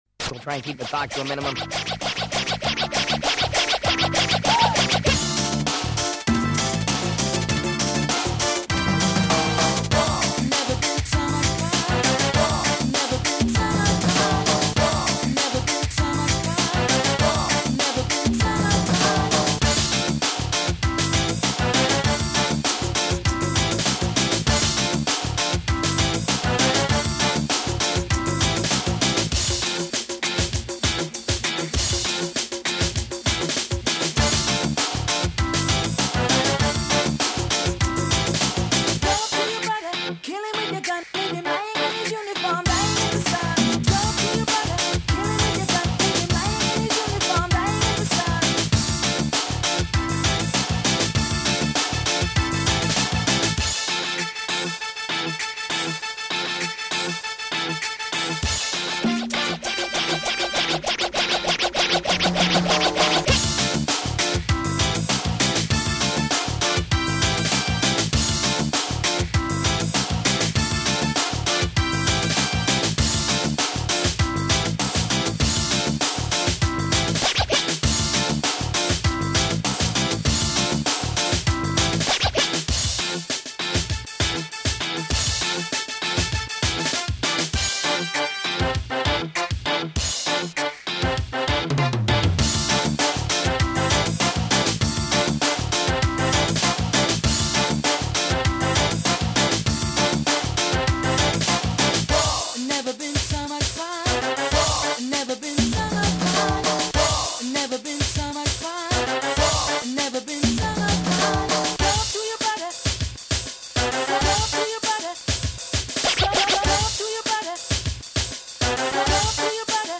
Но все впечатление портит отвратное качество записи.
Кстати, ни миди-клавы, ничего не применялось - голый трекер..
PPS единственный момент, что мр3 тоже моно.